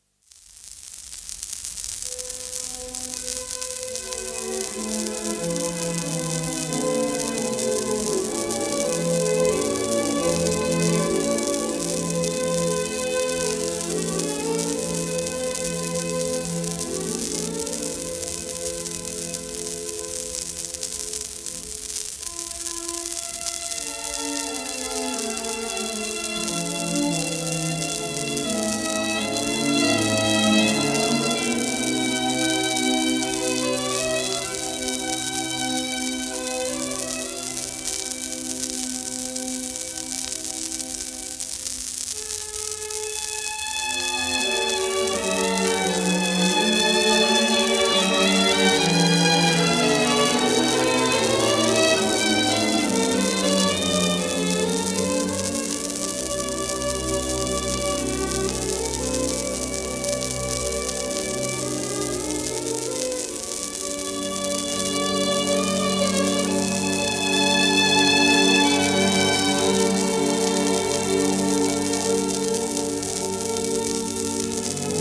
1935年録音